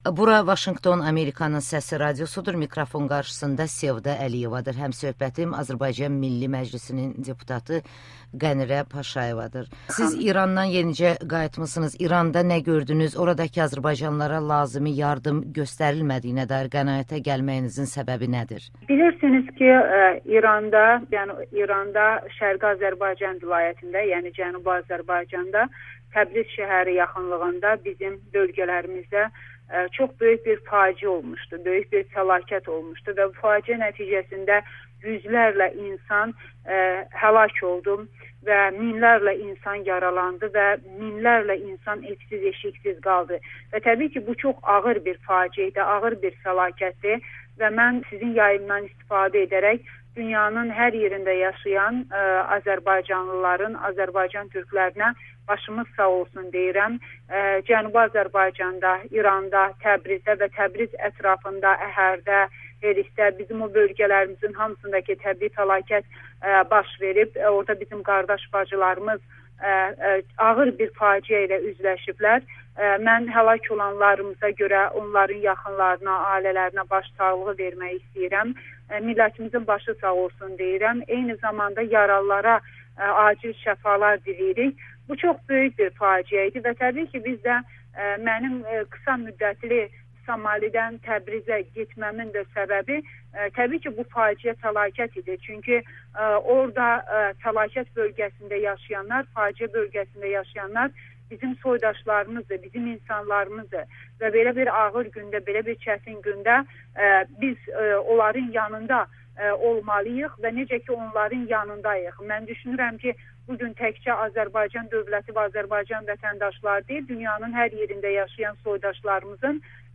Azərbaycan Milli Məclisinin deputatı Amerikanın səsinə danışdı
Qənirə Paşayeva ilə müsahibə